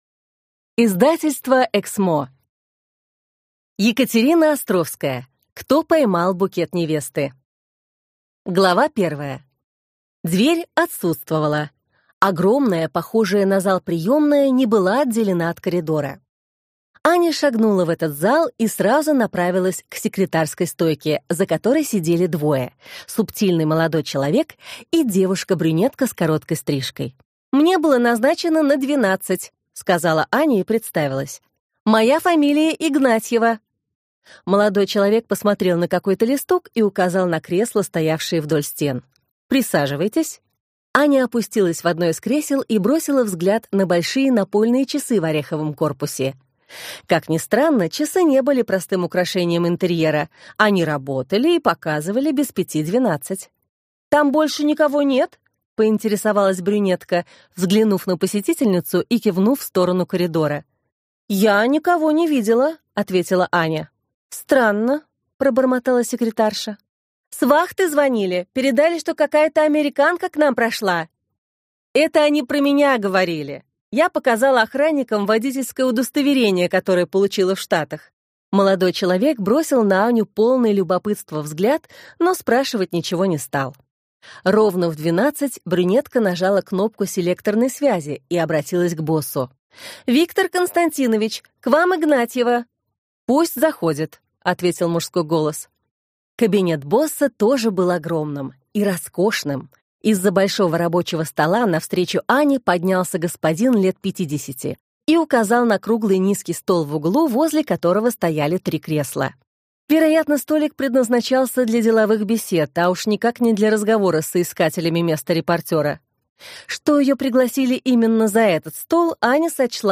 Аудиокнига Кто поймал букет невесты - купить, скачать и слушать онлайн | КнигоПоиск